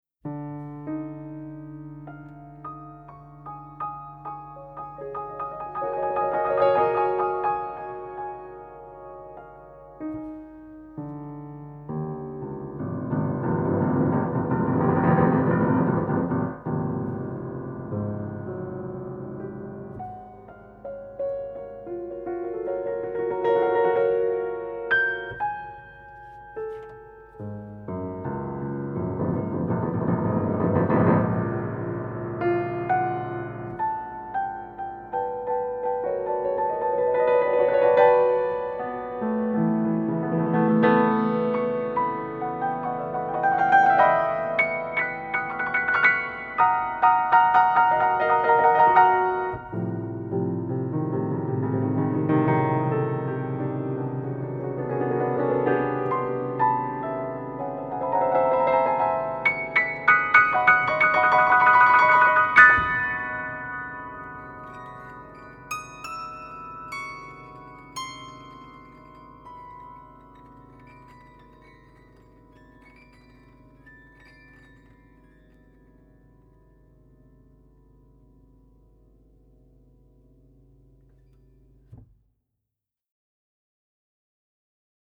per pianoforte